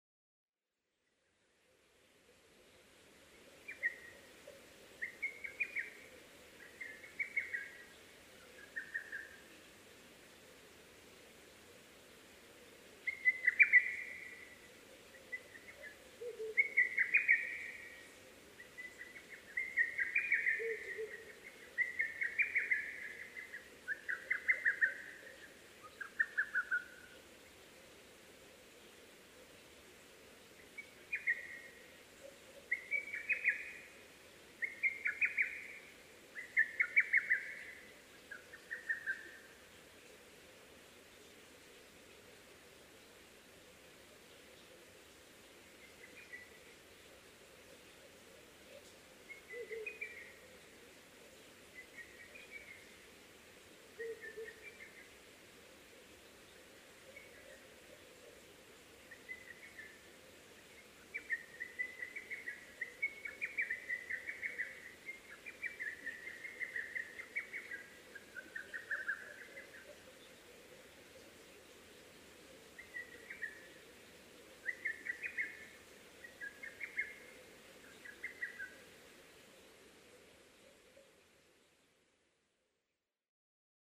ホトトギス　Cuculus poliocephalusカッコウ科
日光市稲荷川上流　alt=1330m
Mic: Panasonic WM-61A  Binaural Souce with Dummy Head
他の自然音：フクロウ、タゴガエル